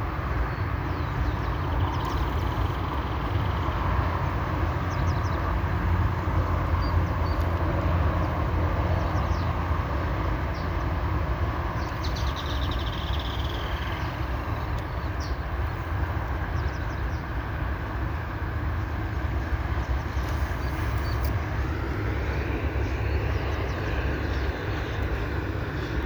Certhiaxis cinnamomeus
Yellow-chinned Spinetail
[ "duet" ]